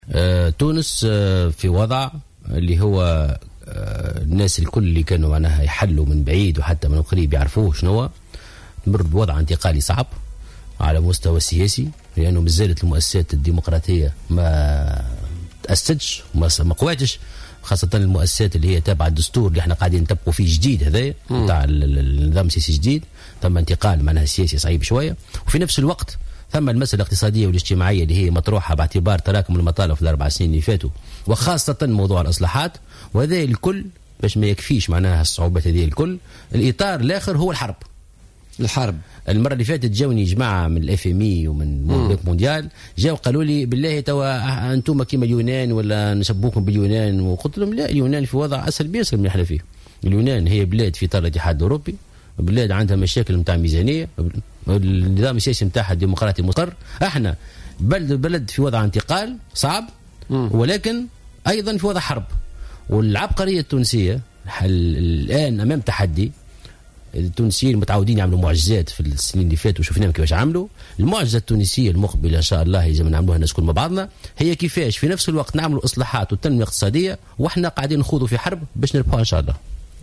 قال الأمين العام لحركة نداء تونس محسن مرزوق في حوار مع إذاعة جوهرة اليوم الاربعاء ، إن تونس تمر بوضع انتقالي صعب على المستوى السياسي خاصة بسبب عدم تركيز المؤسسات الدستورية ، وعلى المستويين الاقتصادي والاجتماعي باعتبار تراكم المطالب في السنوات الماضية.